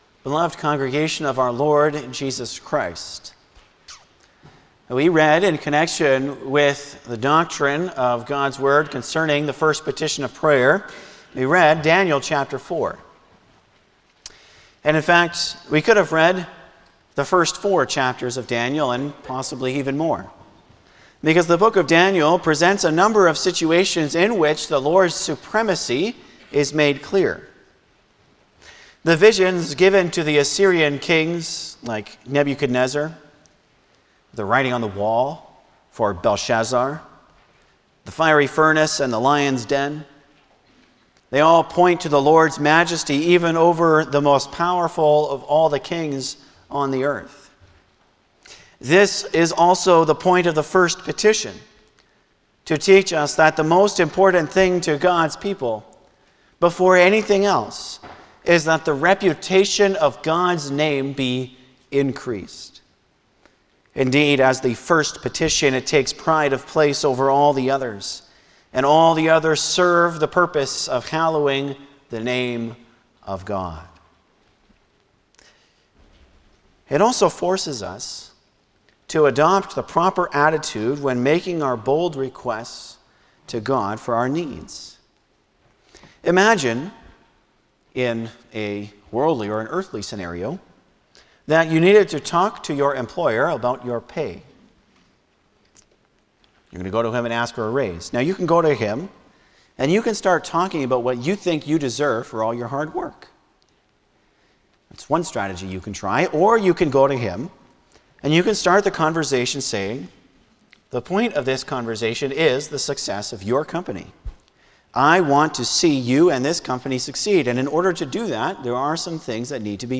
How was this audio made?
Passage: Lord’s Day 47 Service Type: Sunday afternoon